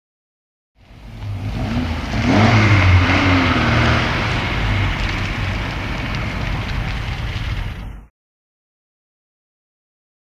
Automobile; In / Stop / Off; Alfa Romeo Up, Stop And Switch Off.